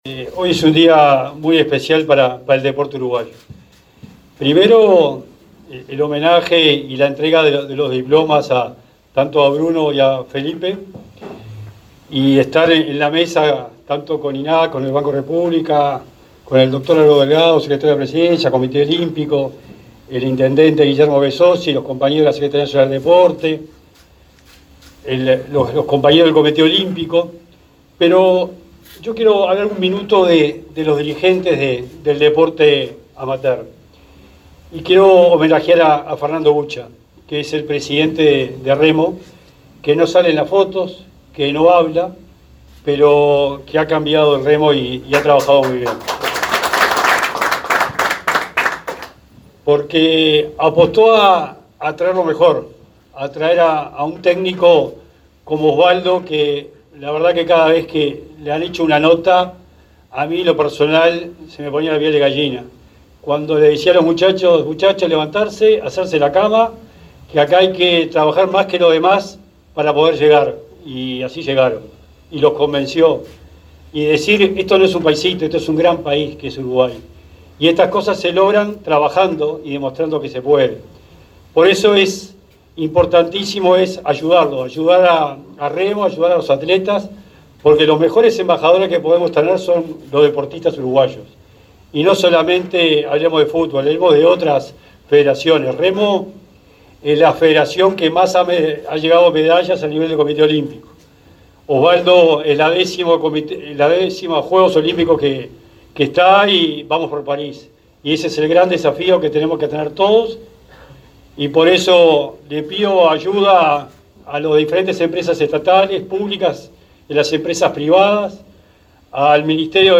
Palabras de Sebastián Bauzá, Javier García y Álvaro Delgado